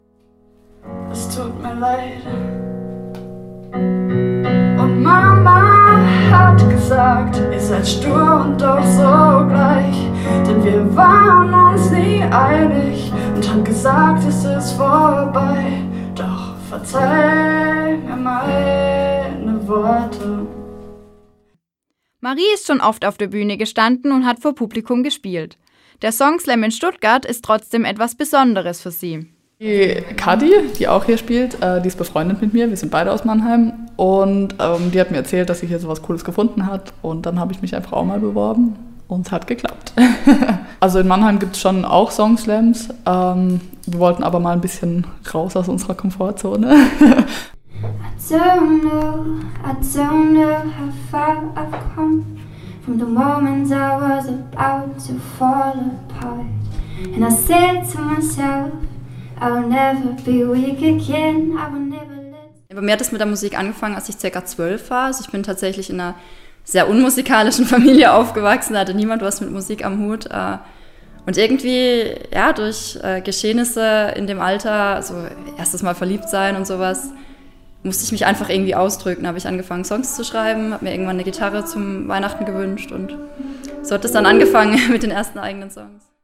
Live-Übertragungen, Musik